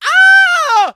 angelo_die_vo_07.ogg